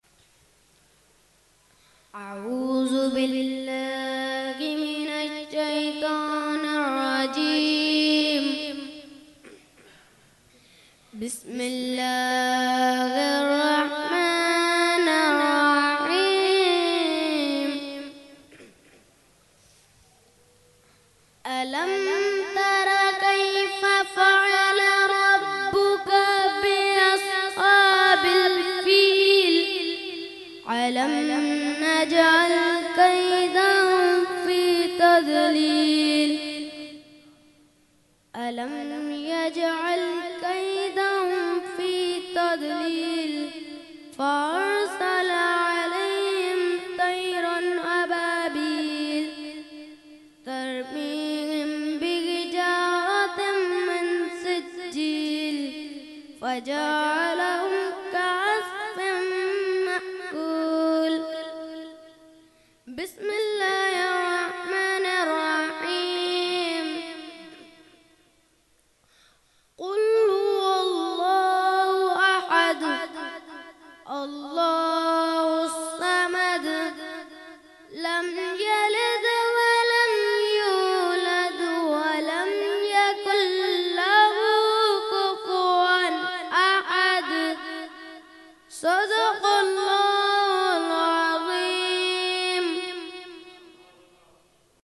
Qirat – 11veen Shareef 2020 – Dargah Alia Ashrafia Karachi Pakistan
Mehfil e 11veen Shareef held 11 December 2020 at Dargah Alia Ashrafia Ashrafabad Firdous Colony Gulbahar Karachi.